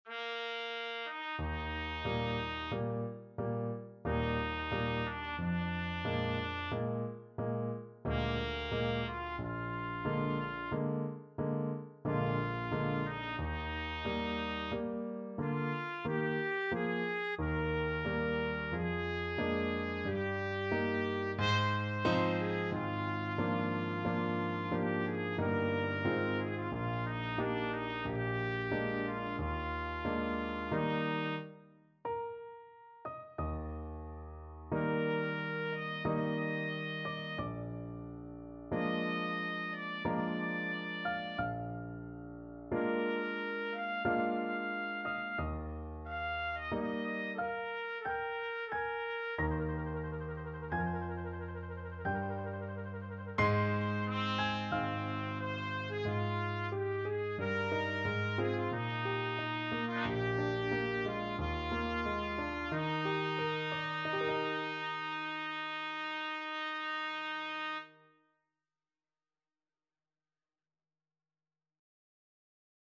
3/4 (View more 3/4 Music)
Adagio =45
Classical (View more Classical Trumpet Music)